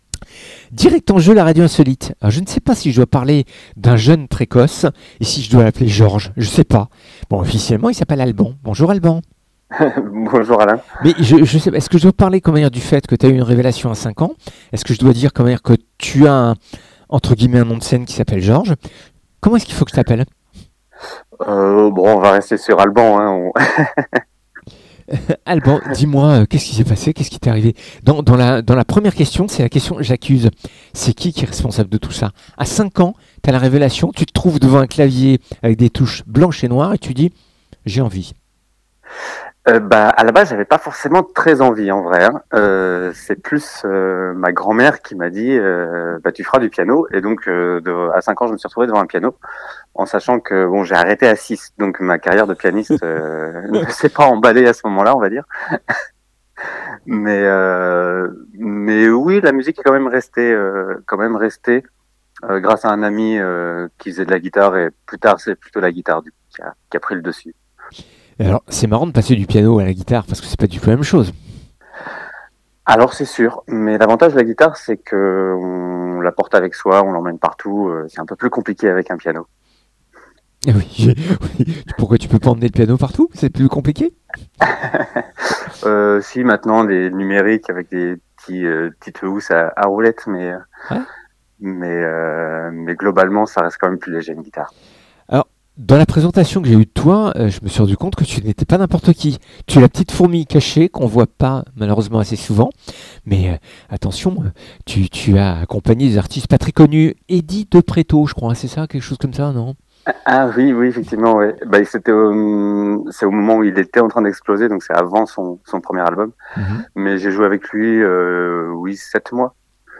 Un duo musical pour vous faire entrer dans la forge secrète où Brassens apprit et cisela son art...